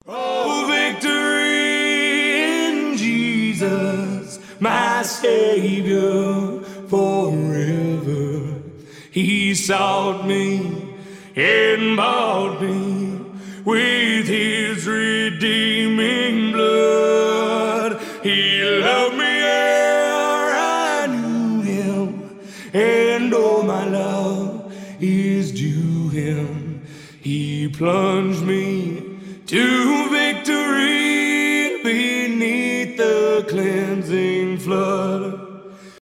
религиозные , христианские
акапелла